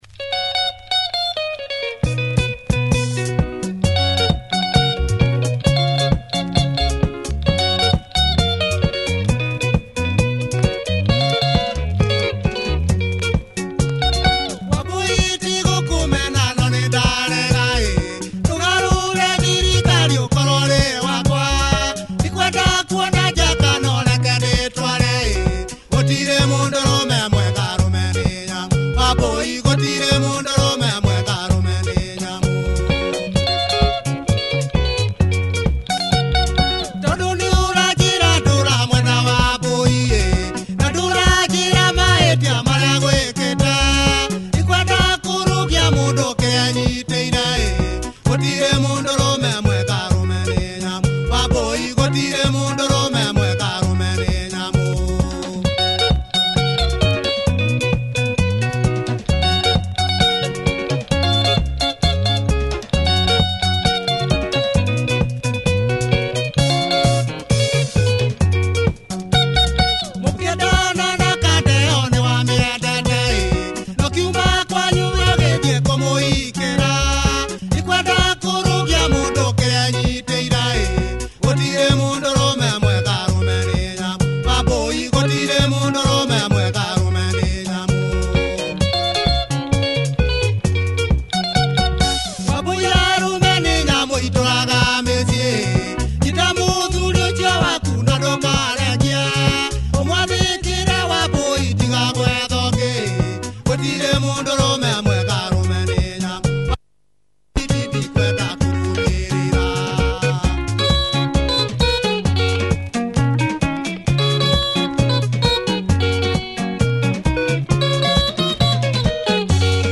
Nice punchy kikuyu benga, good production solid tempo!